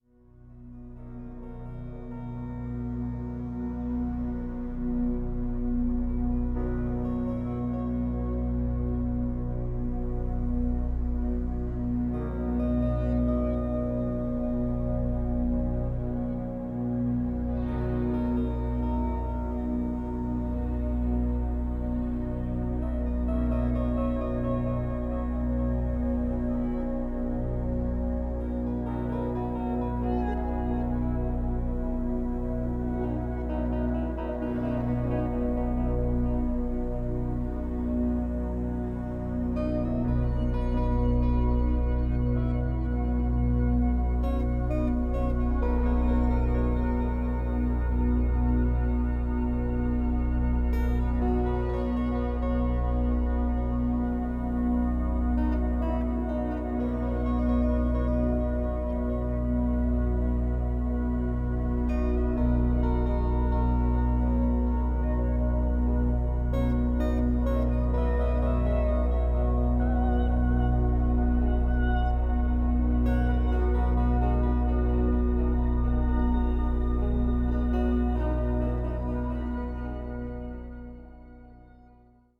Enjoy the spellbinding, healing music